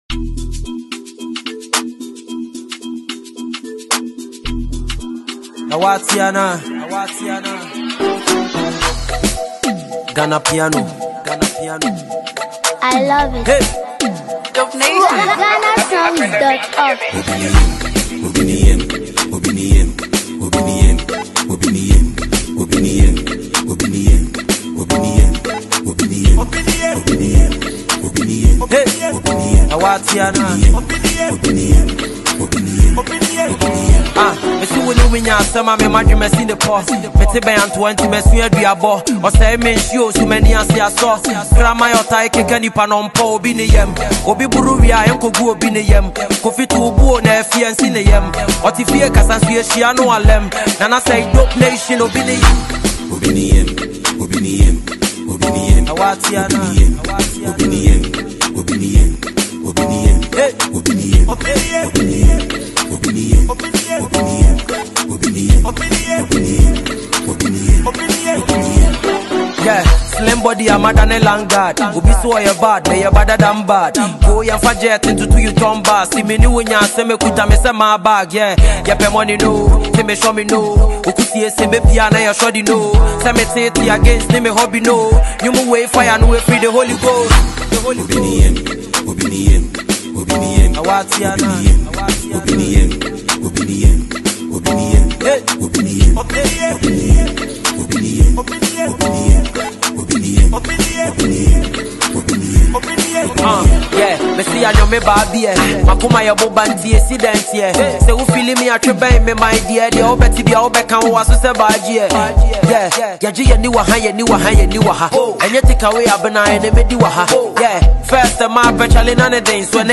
Ghanaian sensational rapper